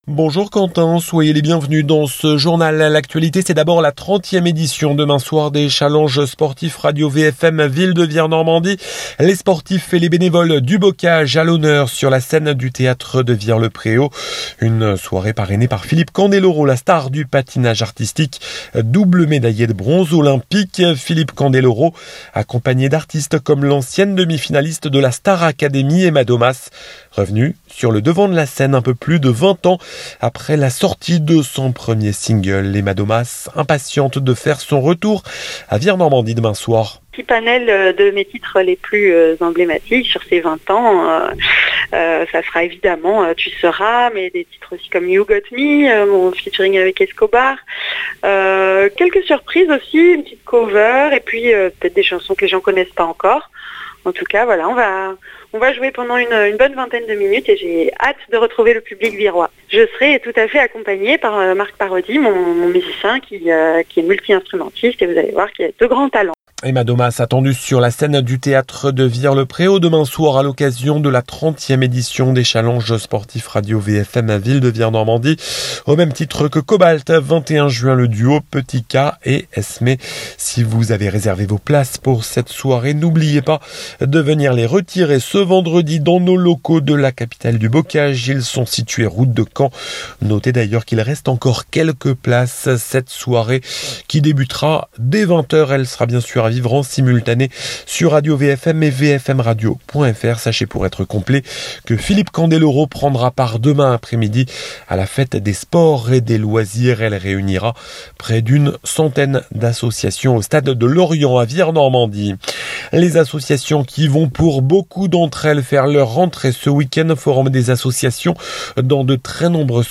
Journal